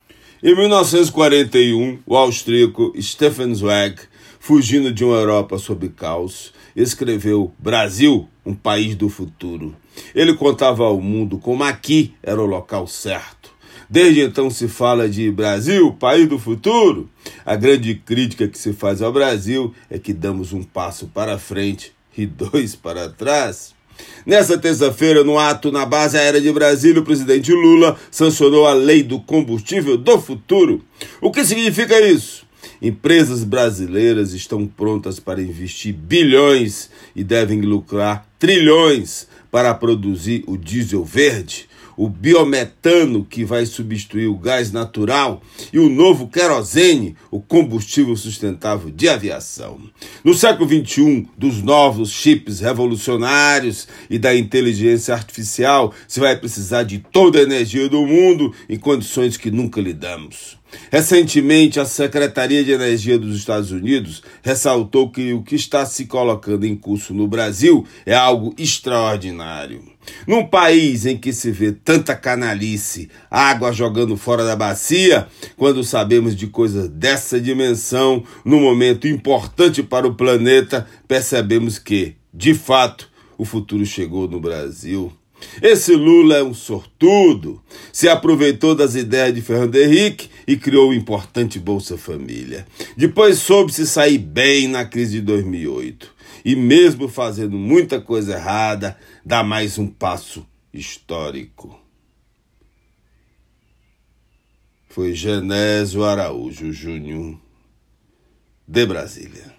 Comentário desta quarta-feira
direto de Brasília.